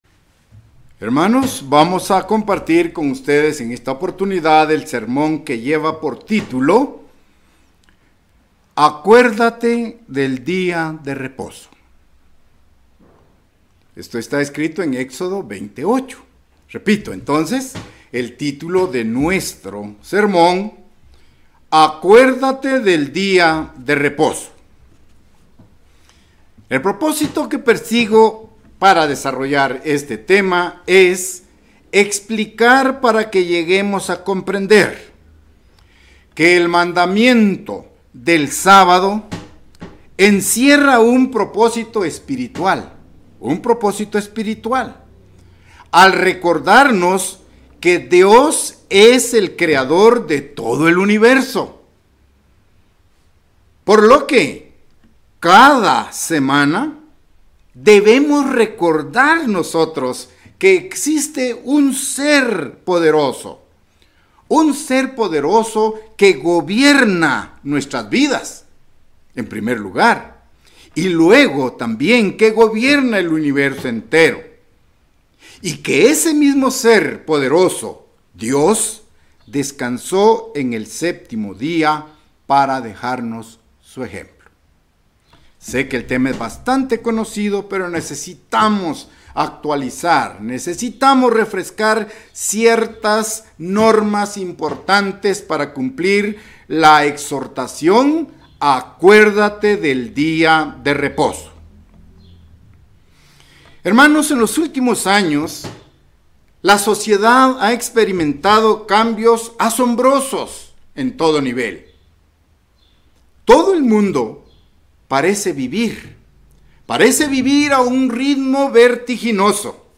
Given in Ciudad de Guatemala